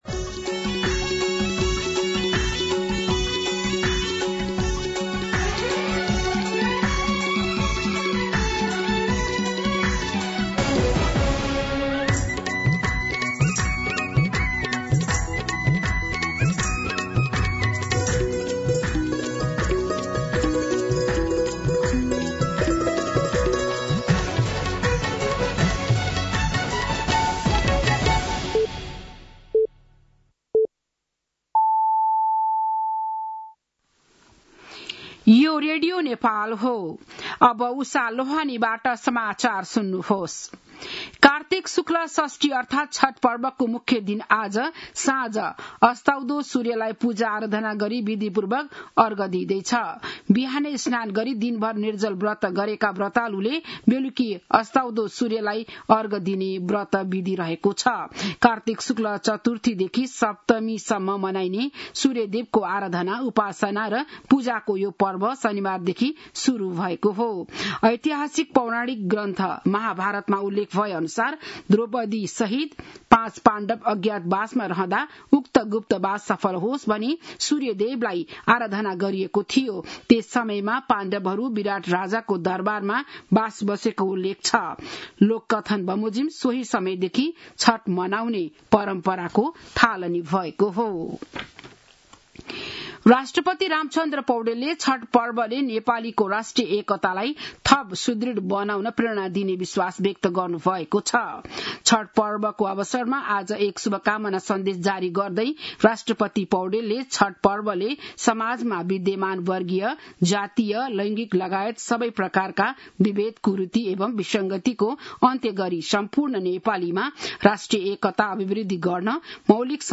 बिहान ११ बजेको नेपाली समाचार : १० कार्तिक , २०८२
11-am-Nepali-News-10.mp3